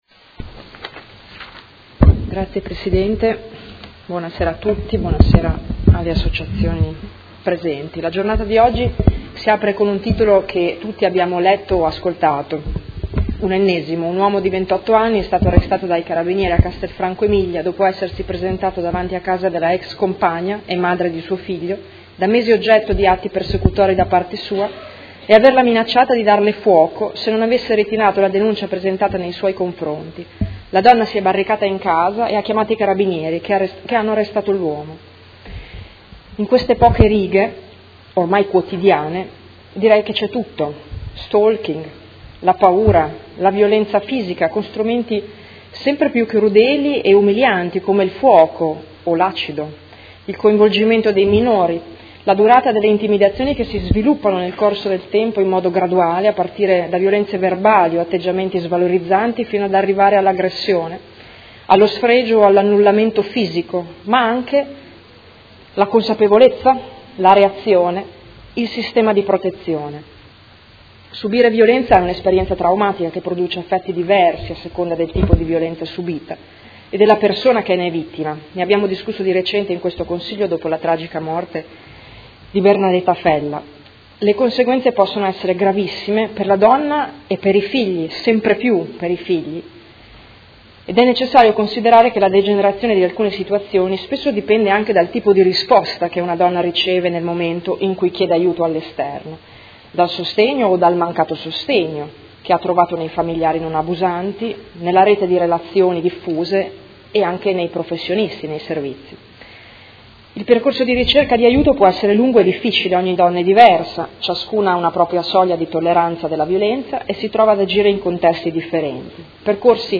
Seduta del 24/11/2016 Dibattito sulla giornata internazionale per l'eliminazione della violenza contro le donne.